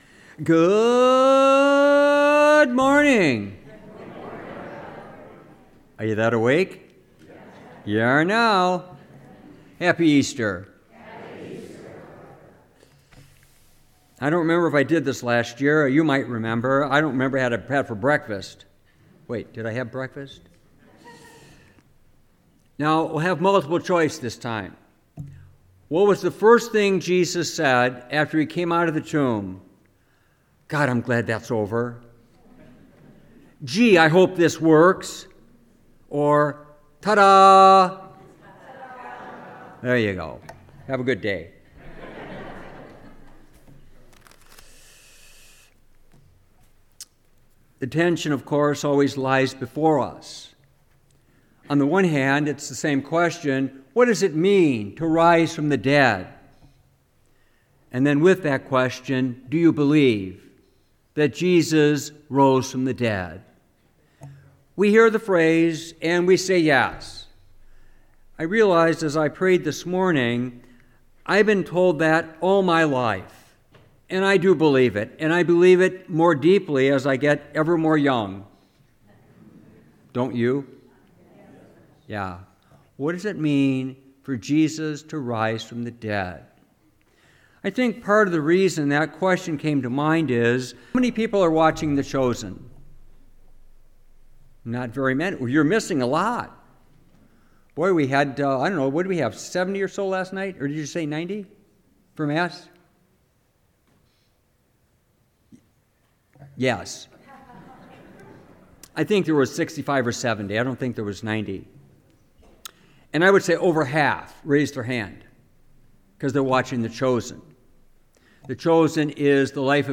Homily, Easter 2023
Homily-Easter-Sunday23-.mp3